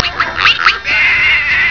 snd_13070_Pig.wav